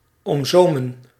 Ääntäminen
UK : IPA : /skɜːt/ US : IPA : /skɜ˞t/